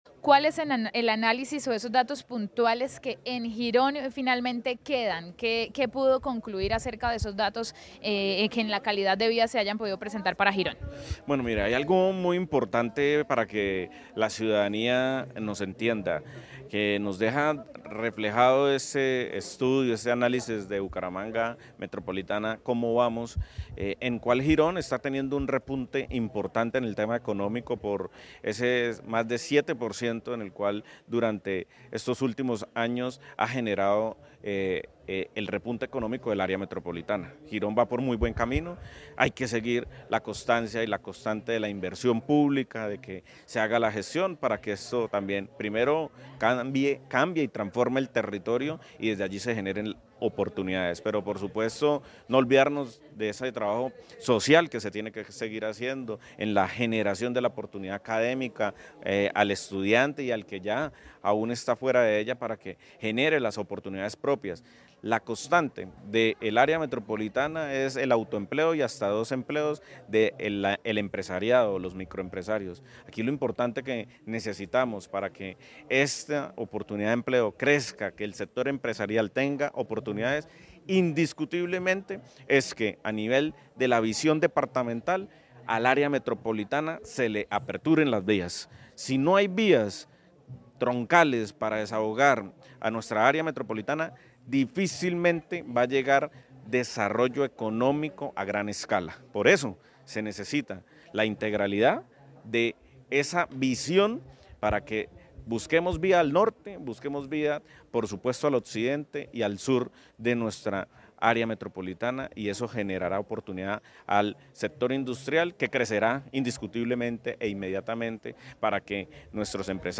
Campo Elías Ramírez, alcalde de Girón